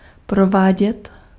Ääntäminen
France: IPA: [e.fɛk.tɥe]